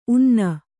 ♪ unna